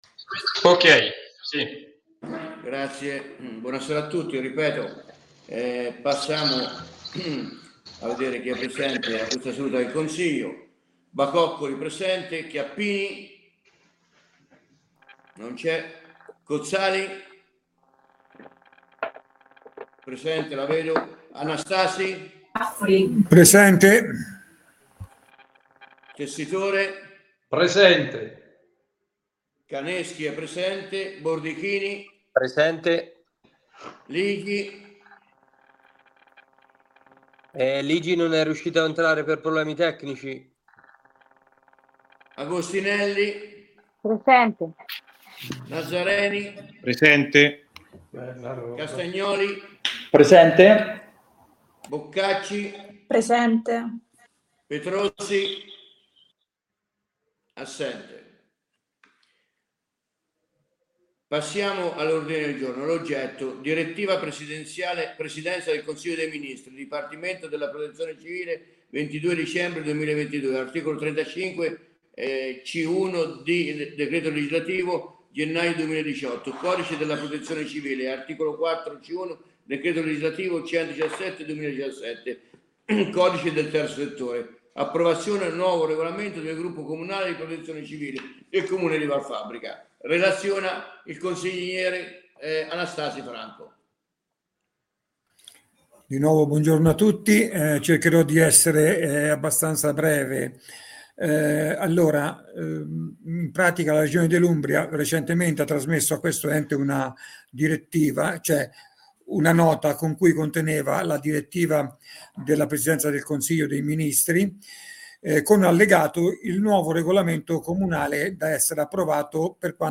Consiglio Comunale del 29 Agosto 2023